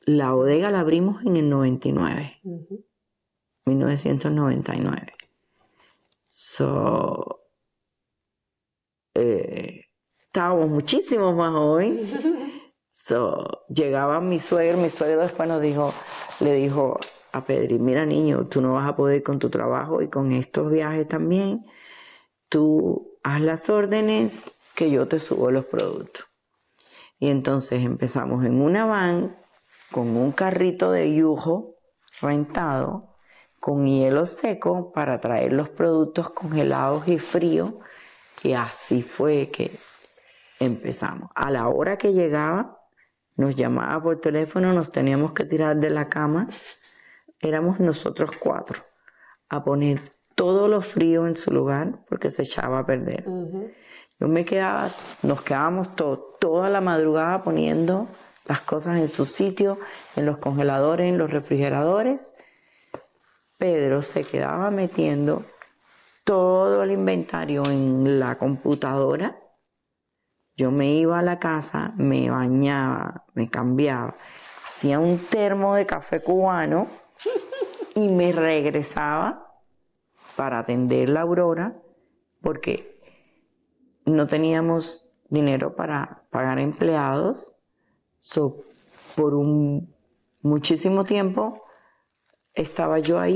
Clip from interview